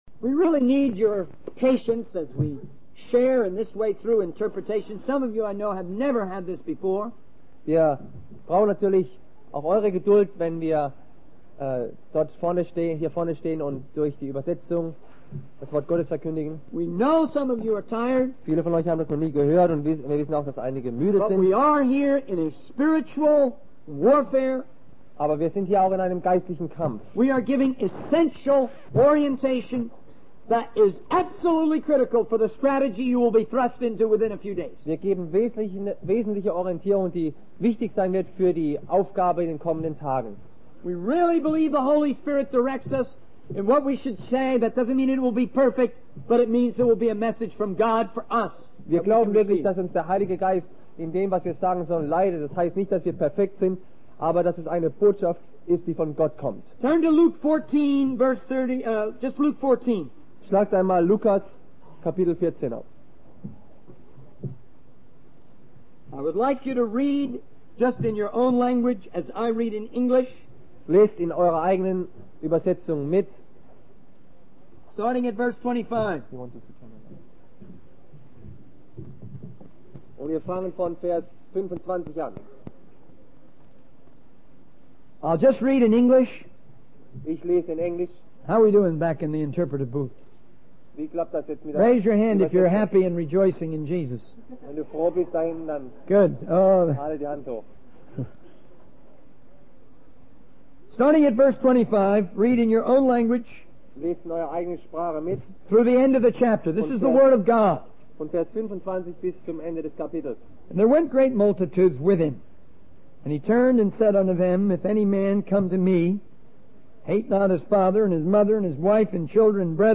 In this sermon, the speaker emphasizes the importance of having a balanced understanding of truth in order to experience true reality. He highlights the need for a vision that aligns with God's plan to bring a harvest of people around the world.